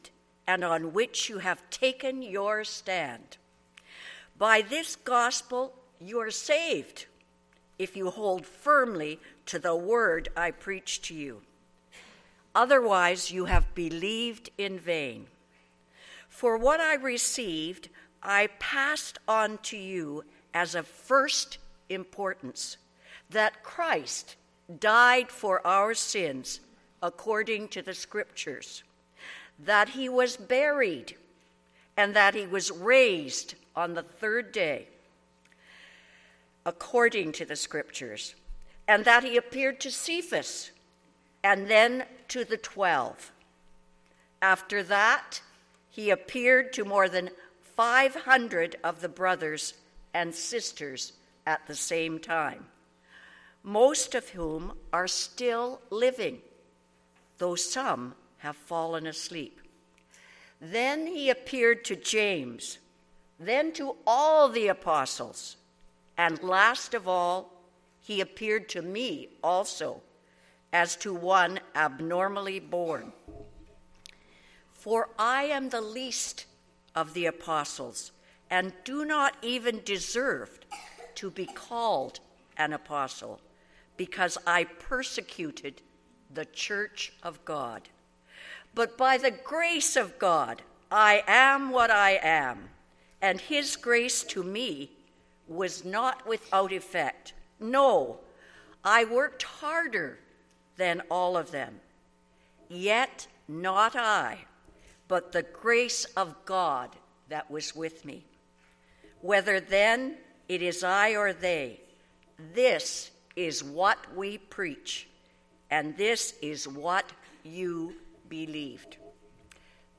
I Corinthians 15:1-11 Service Type: 10:30 AM Service « Hosanna to the Son of David!